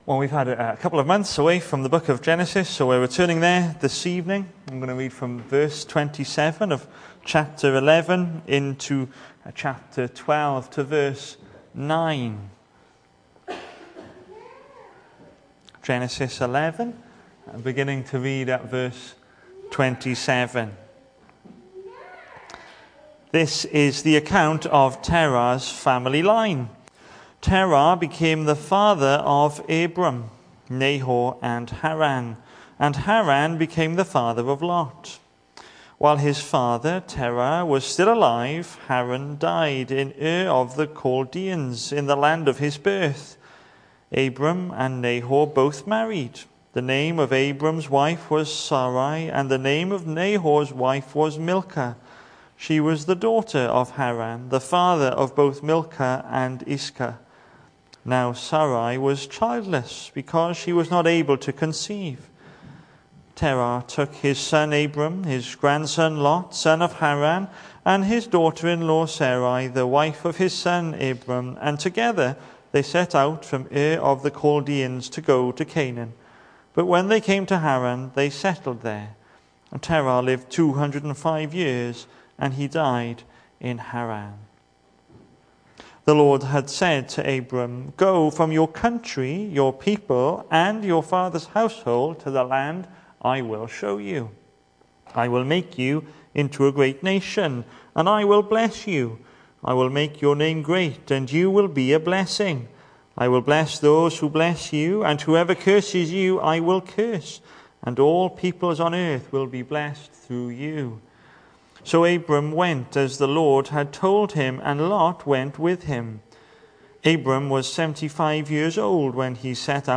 Hello and welcome to Bethel Evangelical Church in Gorseinon and thank you for checking out this weeks sermon recordings.
The 31st of August saw us hold our evening service from the building, with a livestream available via Facebook.